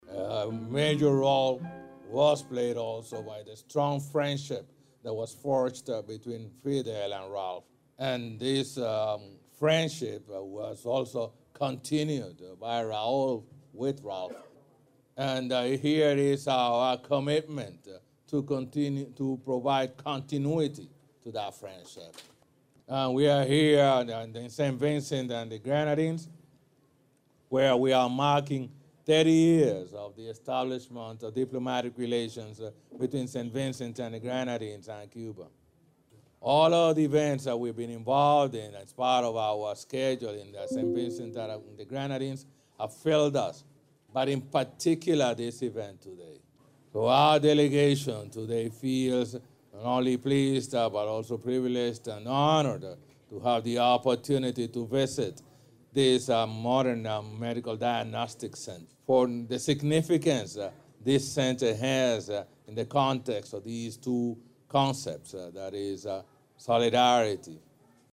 The President said the Modern Health Institution is the result of cooperation and friendship between Cuba and St. Vincent and the Grenadines.